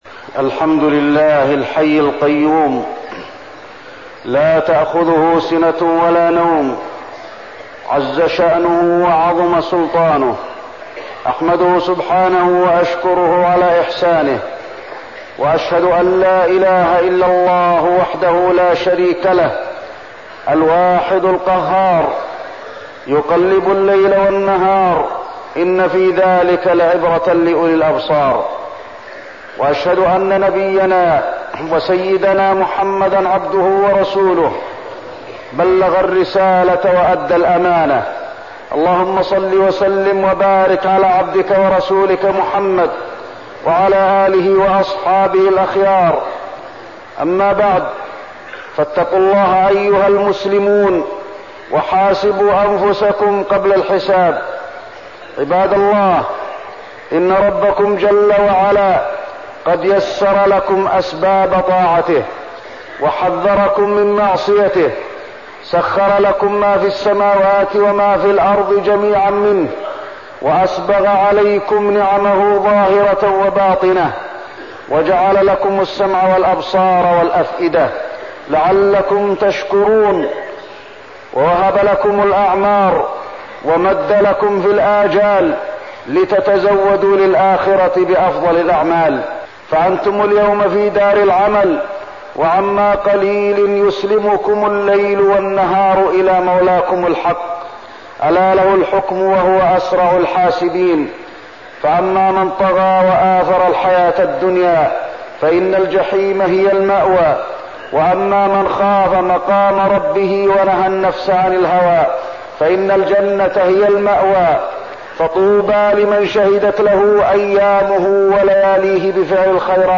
تاريخ النشر ١ محرم ١٤١٥ هـ المكان: المسجد النبوي الشيخ: فضيلة الشيخ د. علي بن عبدالرحمن الحذيفي فضيلة الشيخ د. علي بن عبدالرحمن الحذيفي الهجرة النبوية